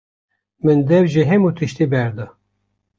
Pronúnciase como (IPA)
/dɛv/